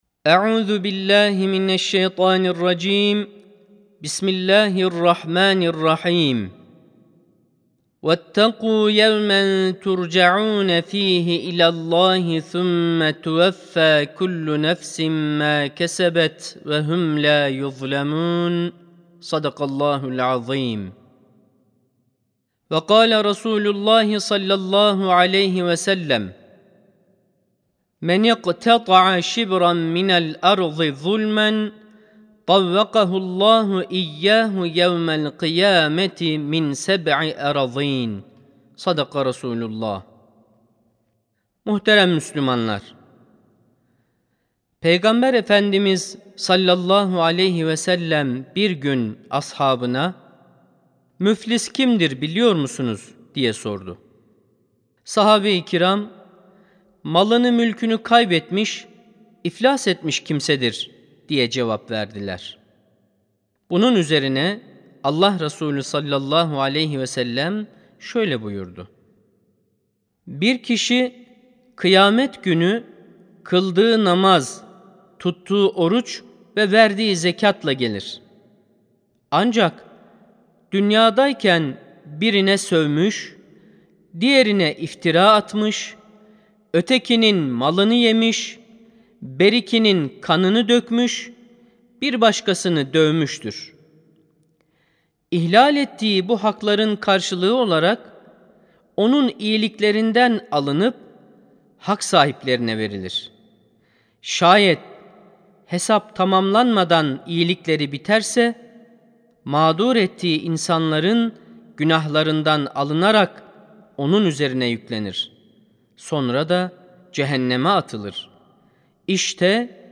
15.08.2025 Cuma Hutbesi: Kul Hakkı Ateşten Gömlektir (Sesli Hutbe, Türkçe, İngilizce, Fransızca, Arapça, İspanyolca, İtalyanca, Almanca, Rusça)
Sesli Hutbe (Kul Hakkı Ateşten Gömlektir).mp3